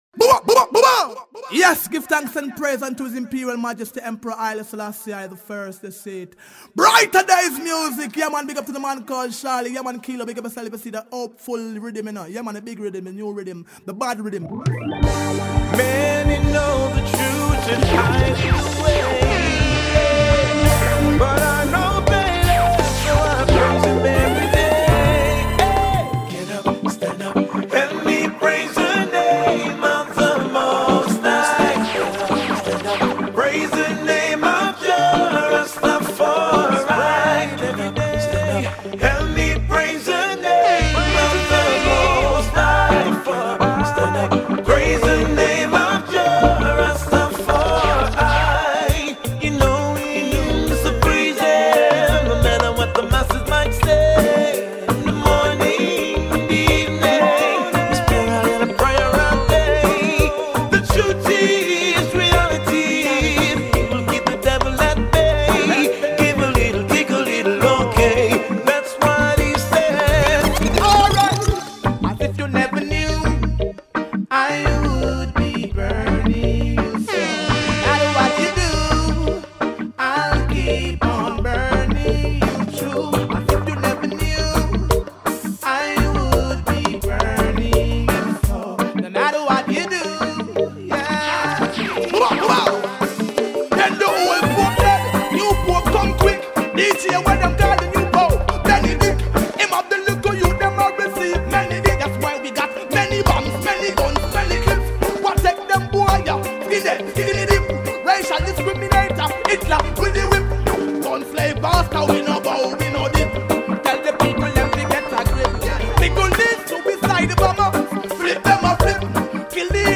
enregistré live
mixpromo_brightadayz_hopefull_riddim.mp3